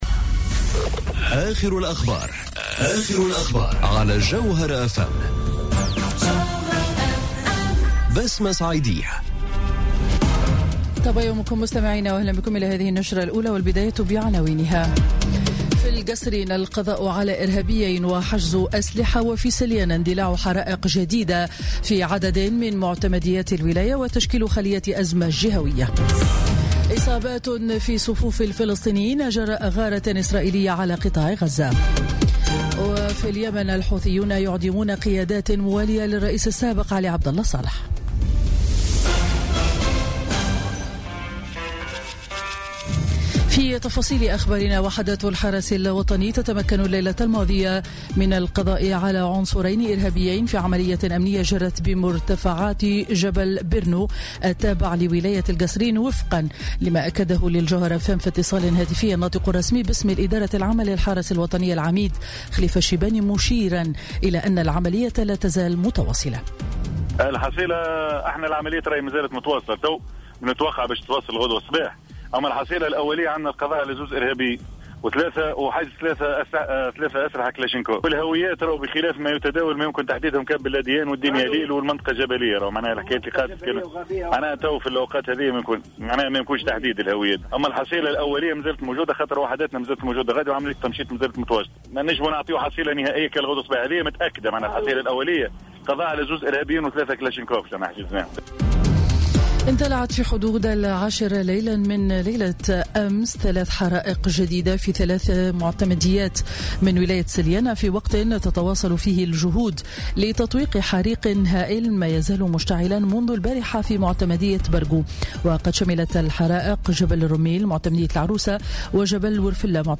نشرة أخبار السابعة صباحا ليوم الأربعاء 9 أوت 2017